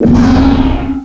pokeemerald / sound / direct_sound_samples / cries / beartic.aif